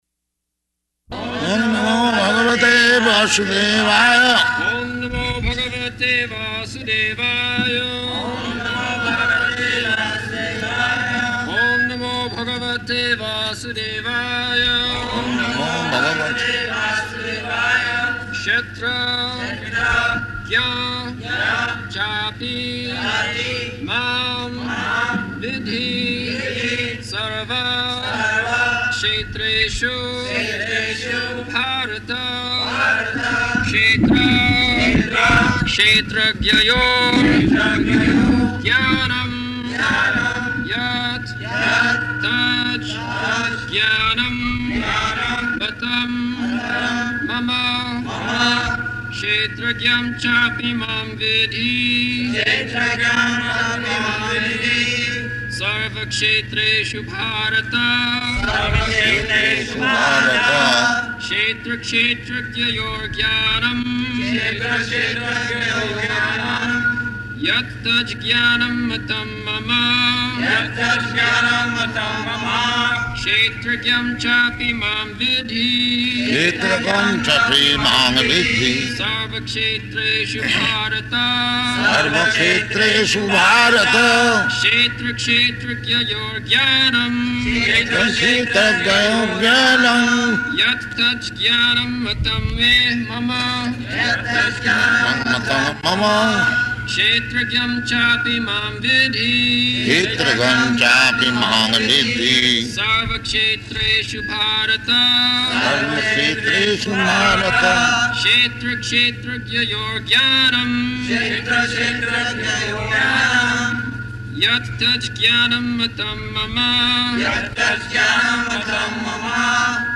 April 19th 1974 Location: Hyderabad Audio file
[Prabhupāda and devotees repeat] [leads chanting of verse]